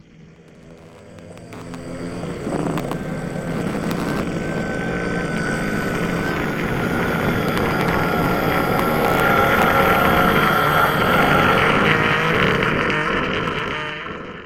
Minecraft Version Minecraft Version 25w18a Latest Release | Latest Snapshot 25w18a / assets / minecraft / sounds / mob / endermen / stare.ogg Compare With Compare With Latest Release | Latest Snapshot